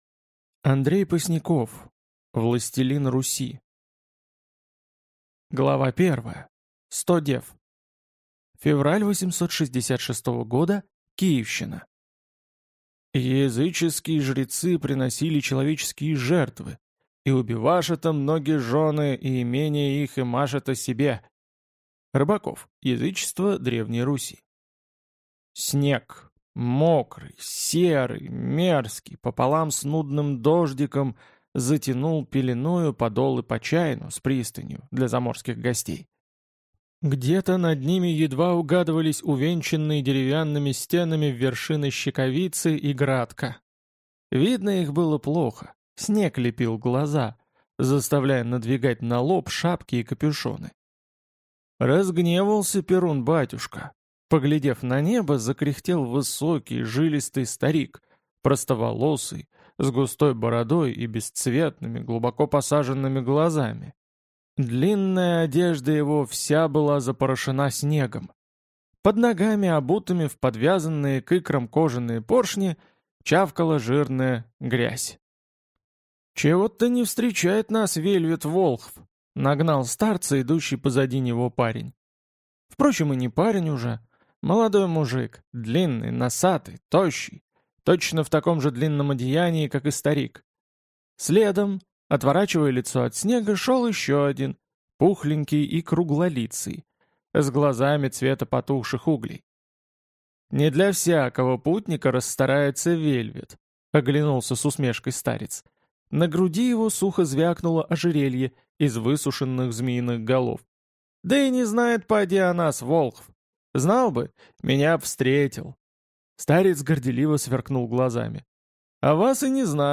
Аудиокнига Властелин Руси | Библиотека аудиокниг